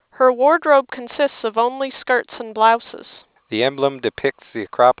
The results presented below are based on measured microphone and loudspeaker data from a conference phone operating in hands-free mode with a far-end speech excitation signal under quiet local talker conditions.
reference speech signal.